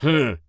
Male_Grunt_Hit_Neutral_08.wav